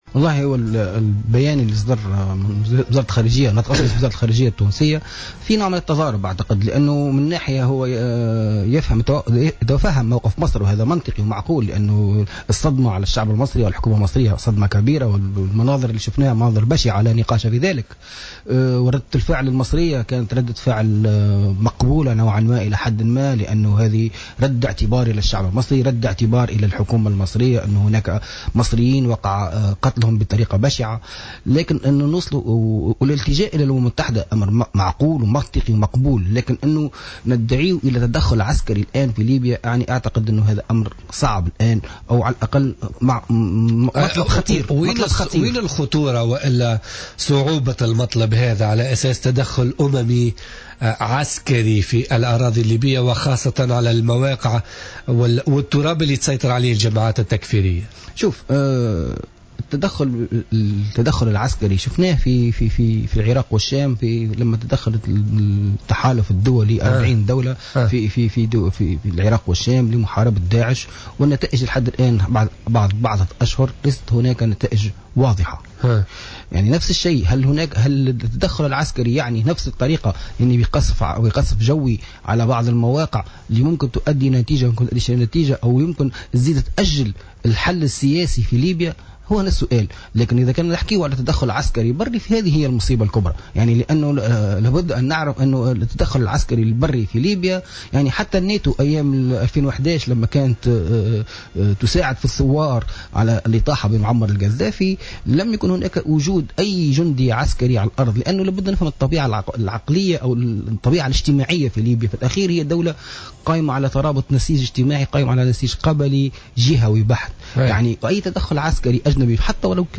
ضيف برنامج بوليتيكا